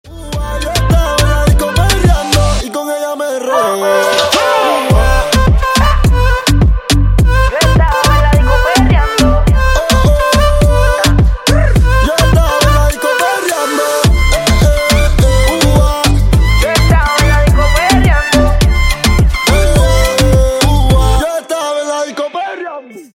Клубные Рингтоны » # Латинские Рингтоны
Танцевальные Рингтоны